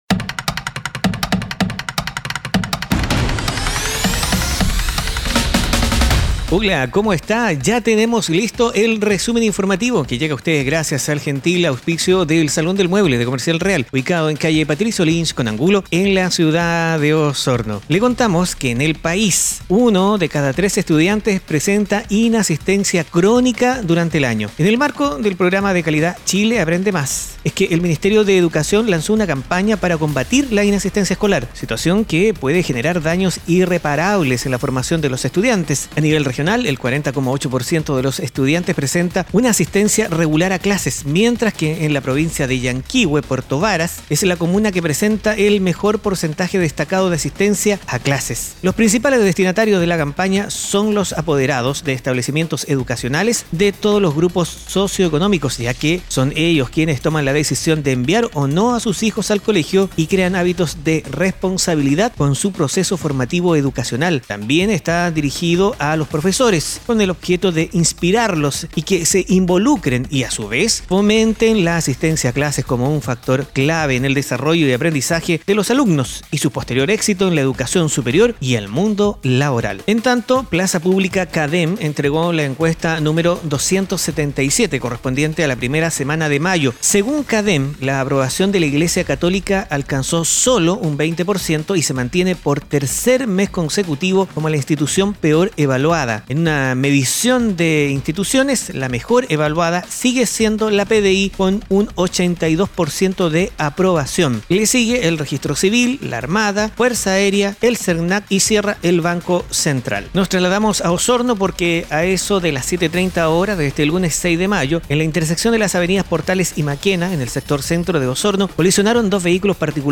Noticias e informaciones en pocos minutos.